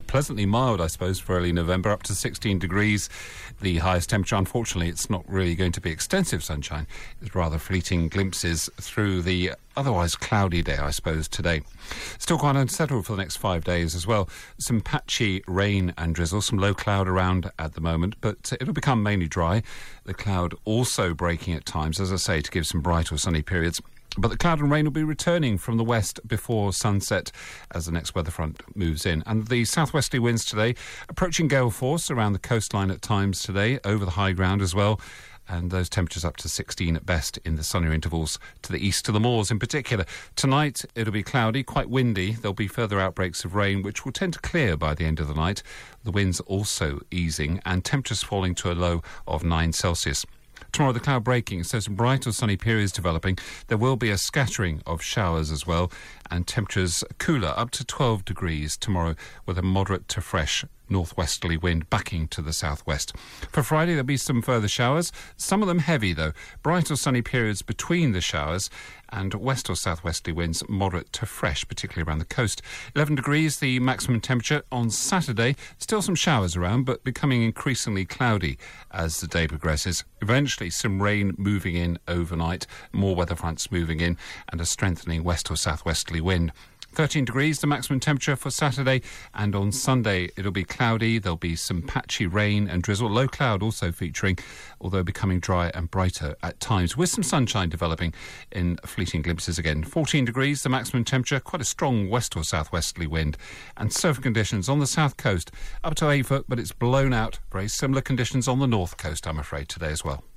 5 day forecast for Devon from 8.35AM on 6 November